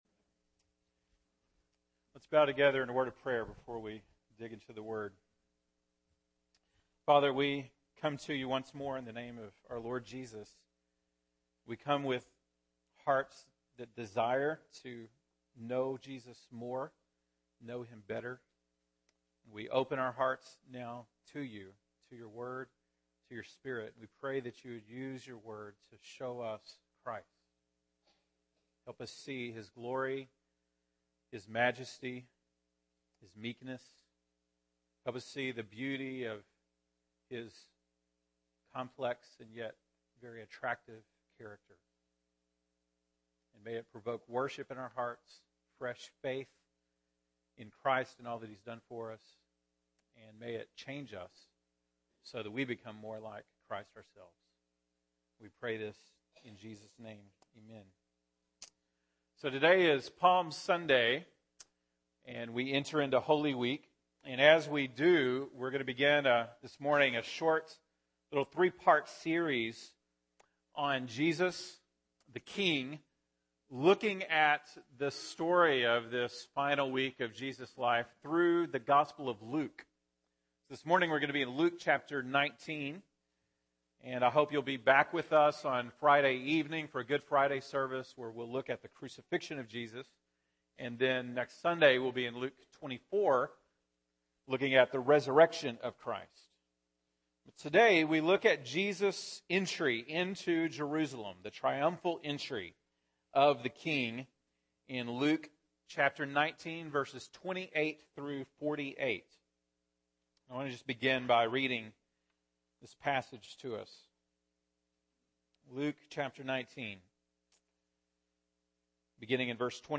March 29, 2015 (Sunday Morning)